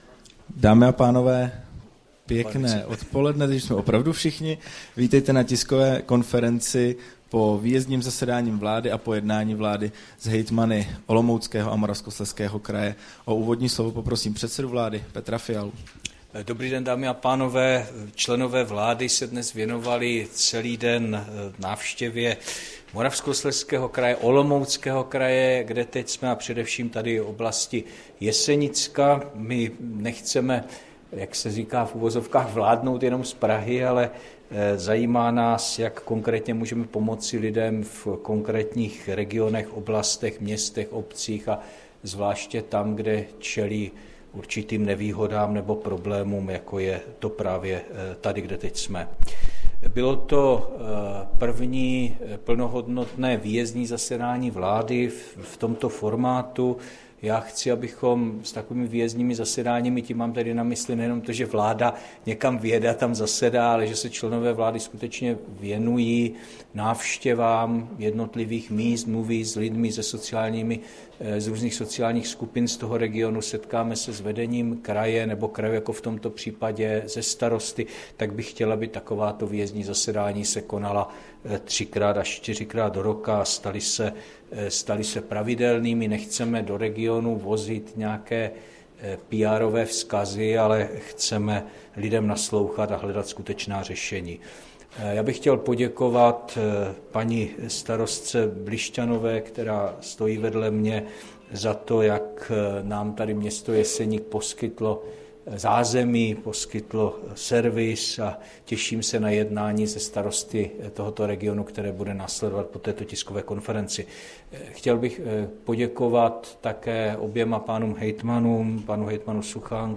Tisková konference po výjezdním zasedání vlády v Jeseníku, 29. března 2023